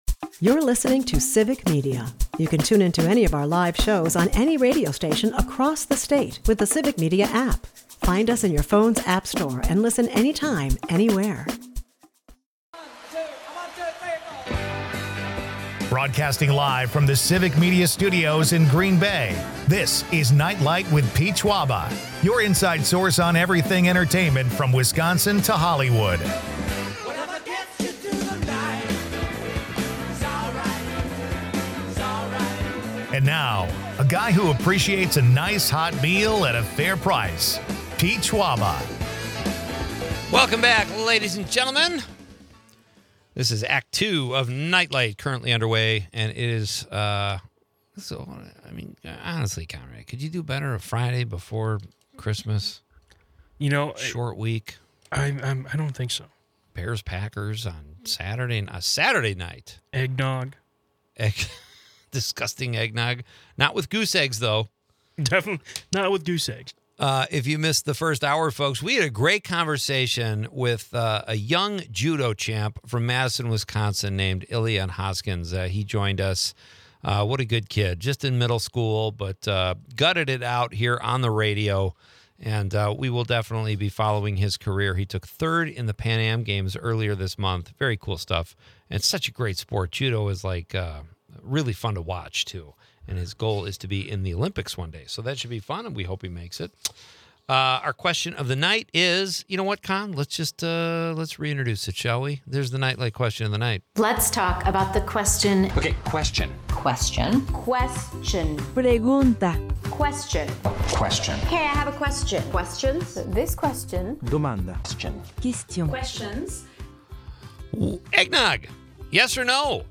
With a mix of humor and nostalgia, they explore the highs and lows of fandom, favorite players, and the impact of key injuries on team performance. The episode is a lively mix of sports banter and holiday cheer.